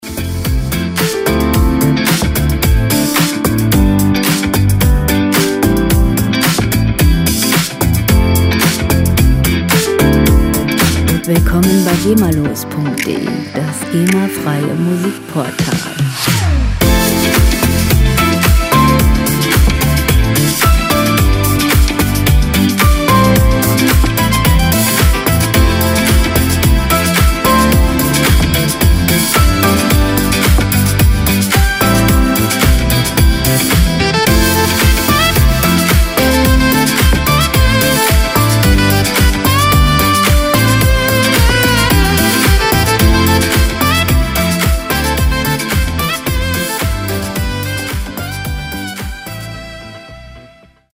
Werbemusik - Lifestyle
Musikstil: Funk Rock
Tempo: 110 bpm
Tonart: A-Moll
Charakter: modern, lebensfroh